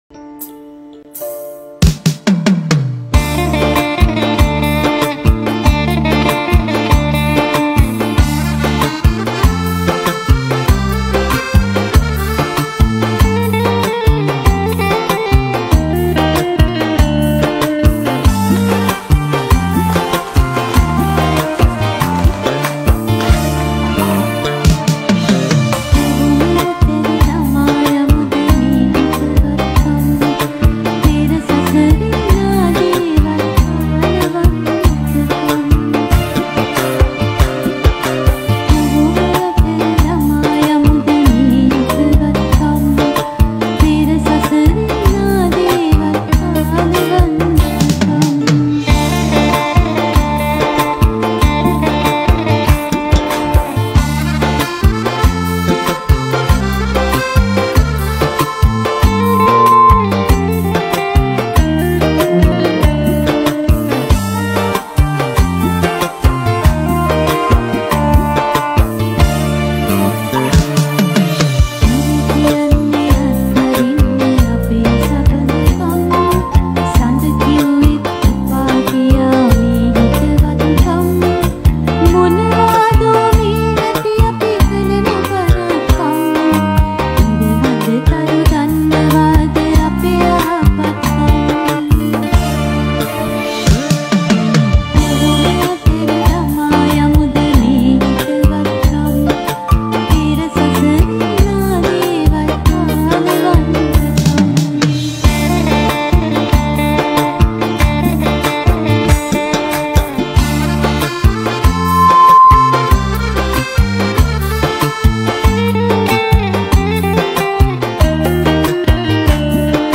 Releted Files Of Sinhala Live Show Single Songs